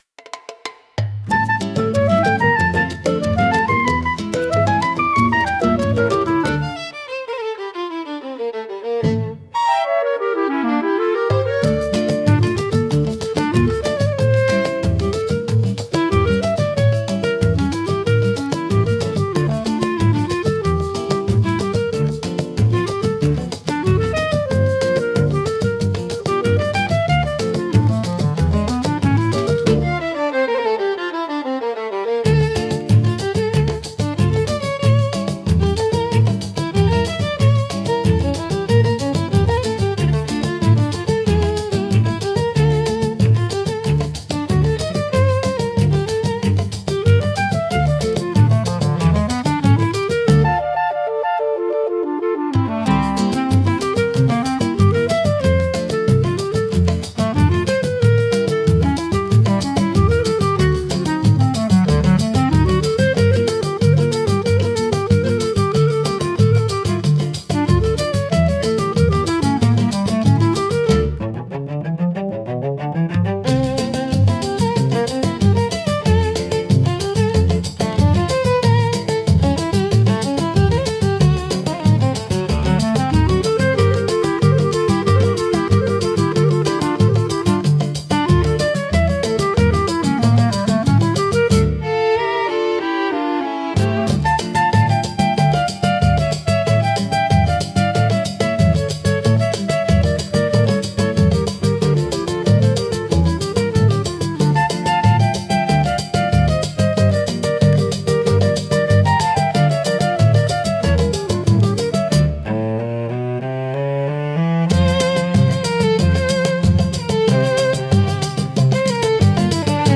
musica, arranjo e voz: IA